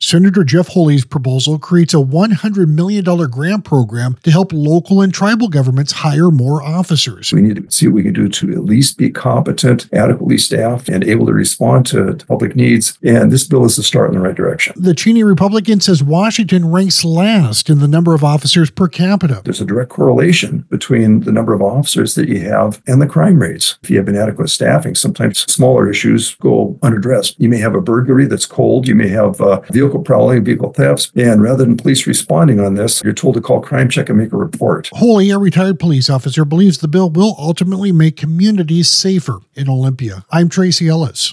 AUDIO: Holy bill seeks $100 million to help hire more law-enforcement officers - Senate Republican Caucus